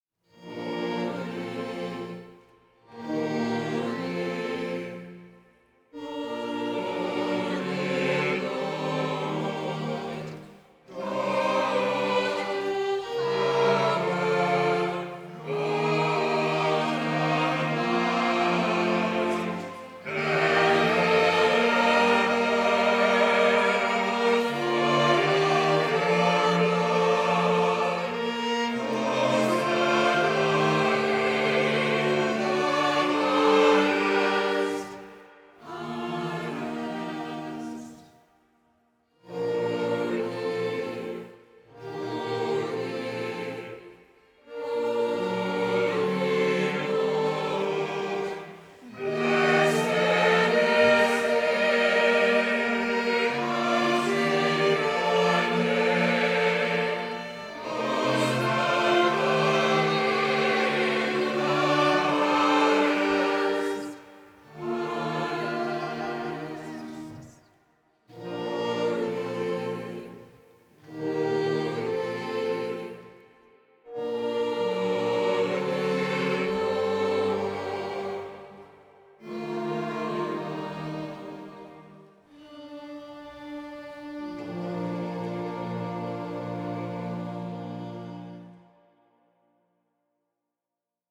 for SATB choir.
Performed by the choir of St Paul's Halifax. These recordings are also accompanied by instrumentalists from the congregation